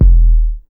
808 RAP K1.wav